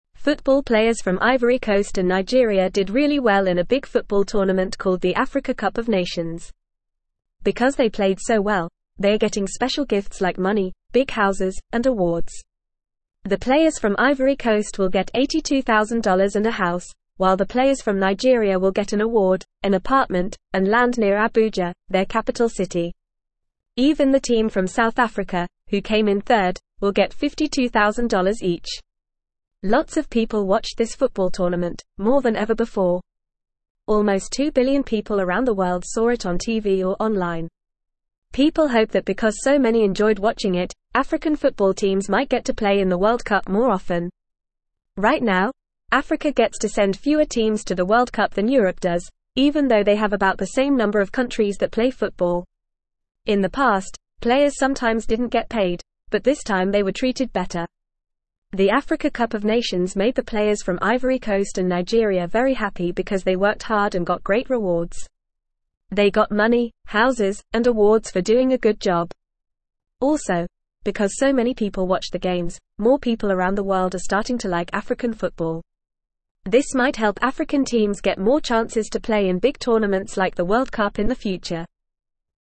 Fast
English-Newsroom-Lower-Intermediate-FAST-Reading-Special-Gifts-for-African-Football-Players-after-Big-Game.mp3